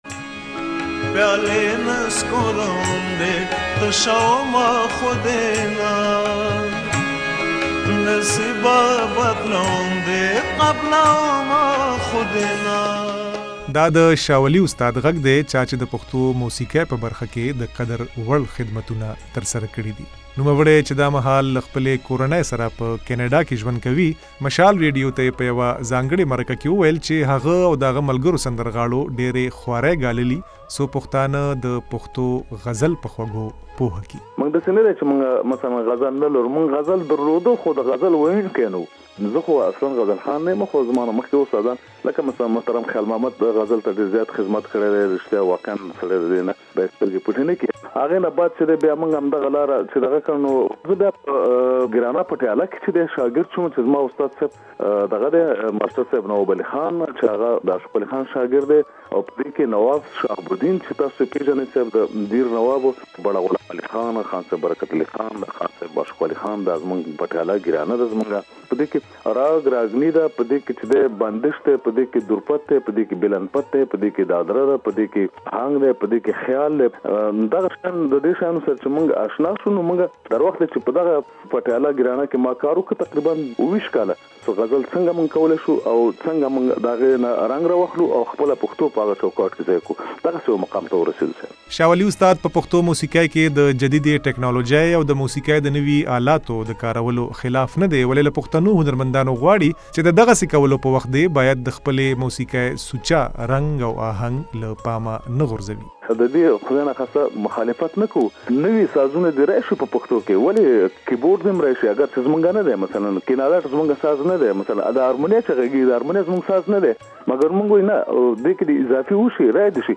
له استاد شا ولي سره د هارون باچا مرکه له دې ځایه واورئ